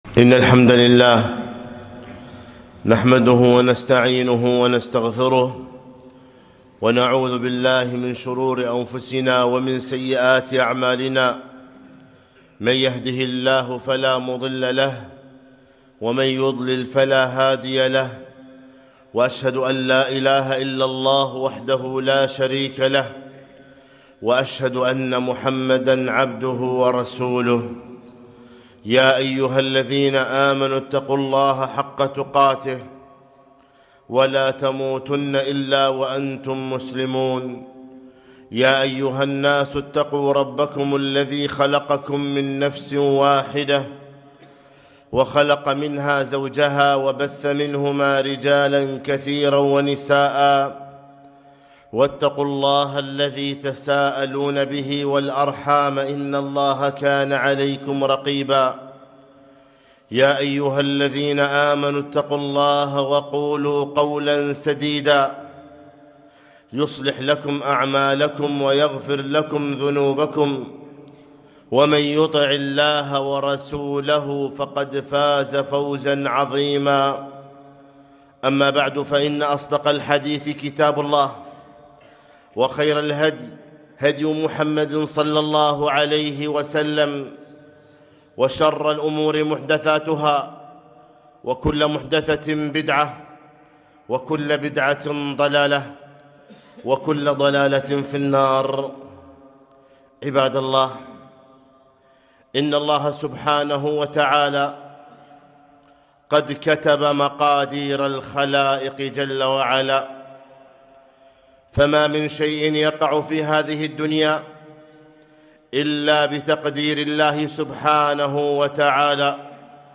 خطبة - الوباء عبرة وعظة